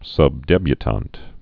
(sŭb-dĕbyə-tänt)